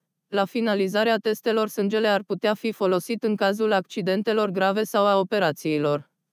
This speech and text dataset has been collected and processed with the available Simple4All tools in order to demonstrate the performance of speaking style adaptation for the Romanian baseline synthetic voice already created from the standard RSS corpus towards the prosody and expressive style of the main presenter of the broadcast news.
– the synthetic baseline voice: [sample 1]
baseline_fem_0003.wav